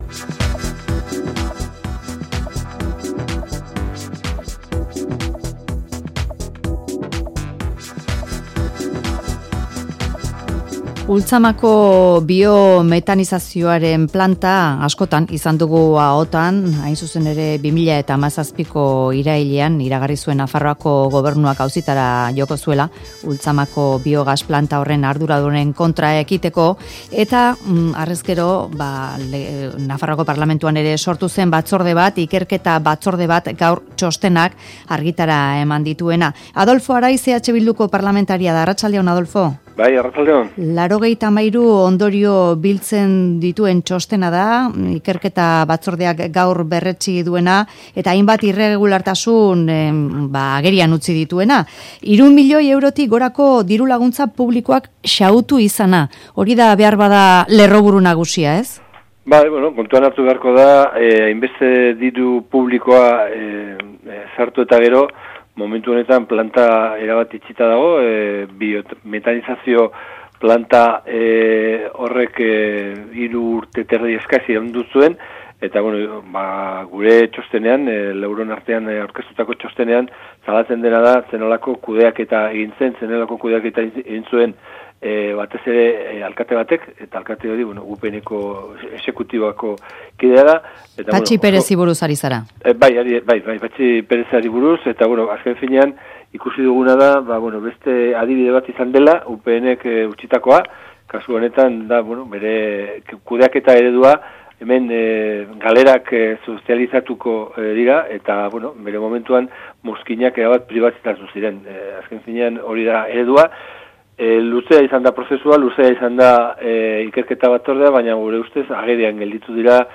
EH Bilduko Adolfo Araiz izan da Mezularian.